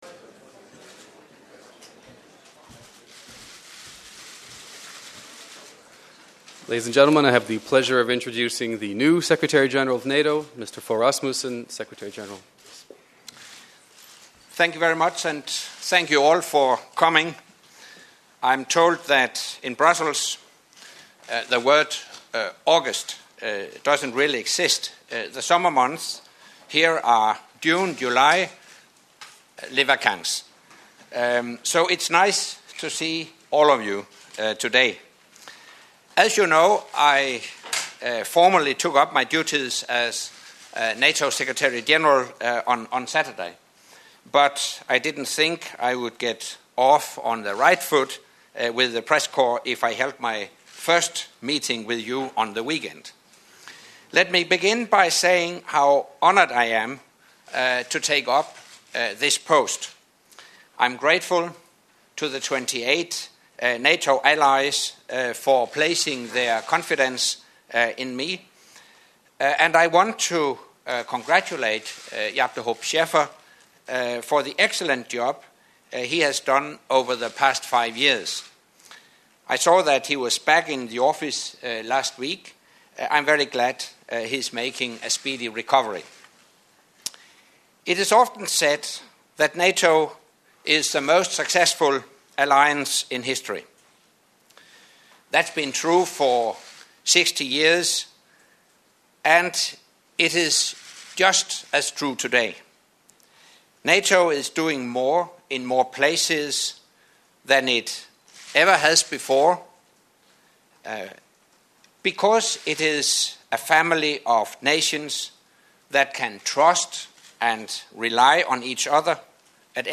First NATO Press conference by Secretary General Anders Fogh Rasmussen